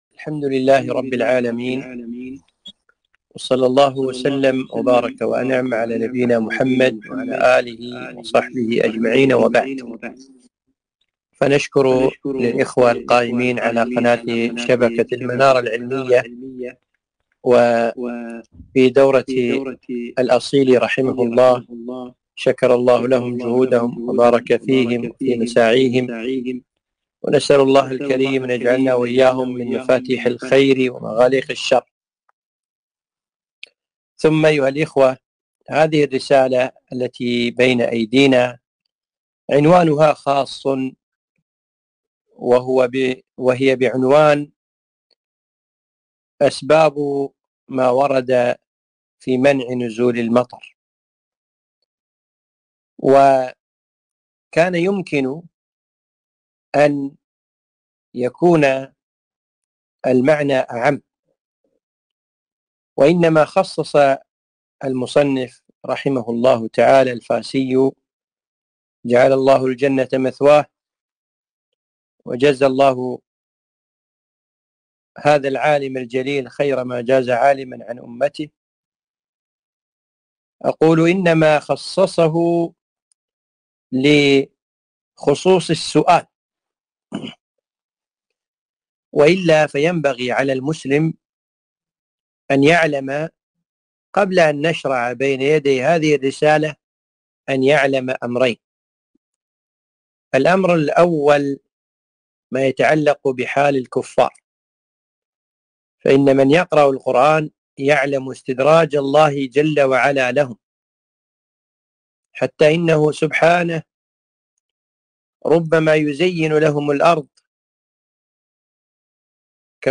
محاضرة - ((جزء فيما ورد في سبب منع نزول المطر)) للحافظ أبي العلاء إدريس العراقي